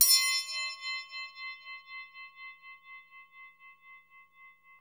Index of /90_sSampleCDs/Roland LCDP03 Orchestral Perc/PRC_Orch Toys/PRC_Orch Triangl
PRC TREM.0BR.wav